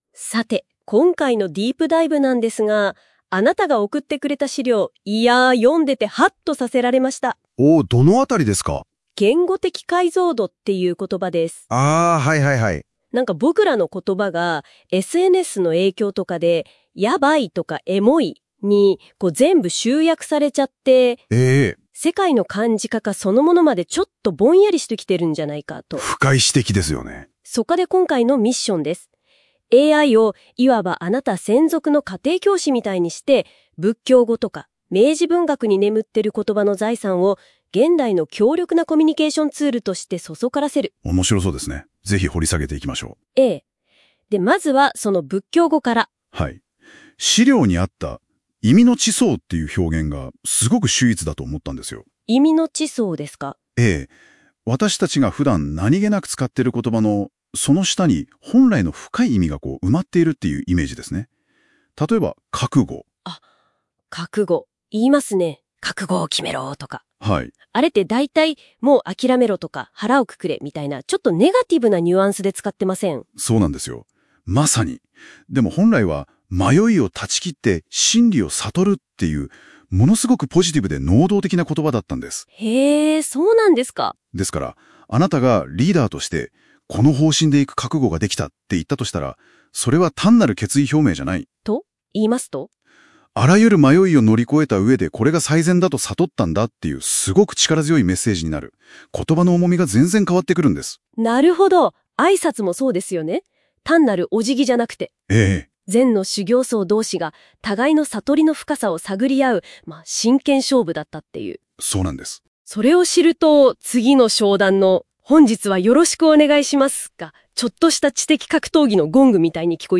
【音声解説】仏教語と文豪に学ぶ言語的解像度。AIで蘇る覚悟・挨拶の深層